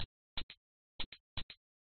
hi hat 10
描述：hi hat
声道立体声